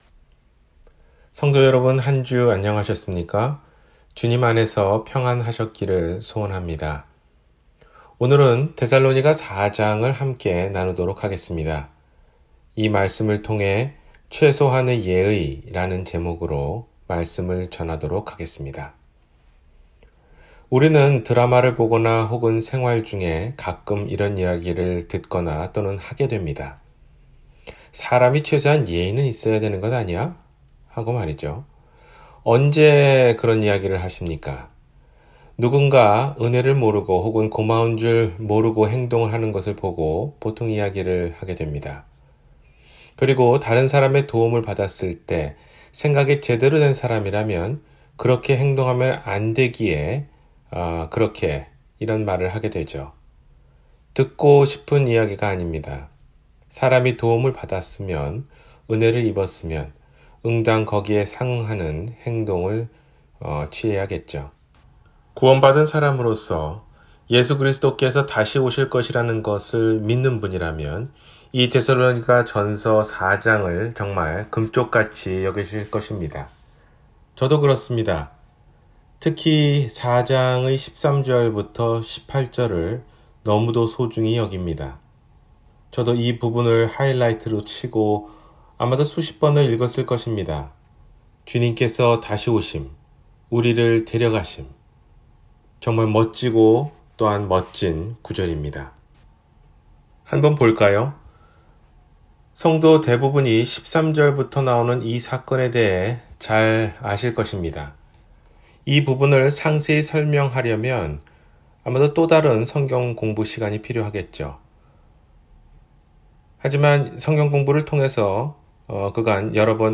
최소한의 예의-주일설교